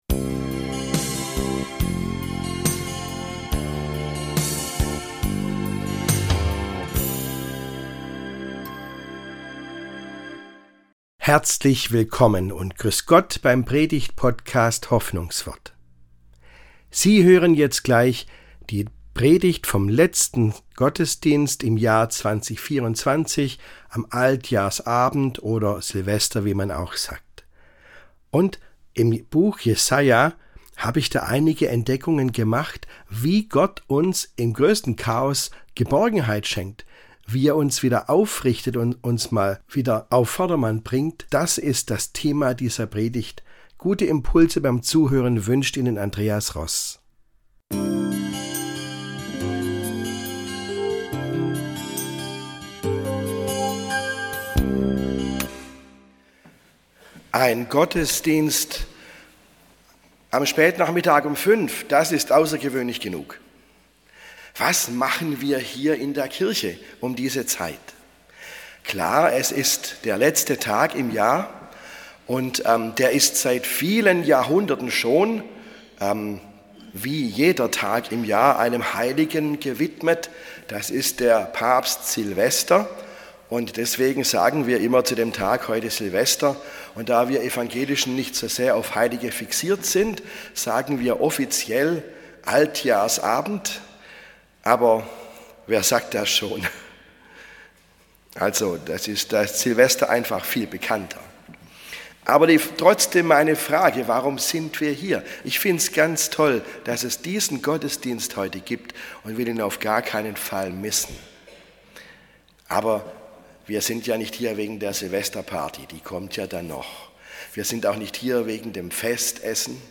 Im letzten Gottesdienst des Jahres 2024 waren Zeilen aus dem Jesajabuch augenöffnend: So also schenkt Gott uns Geborgenheit im Chaos unserer Zeit!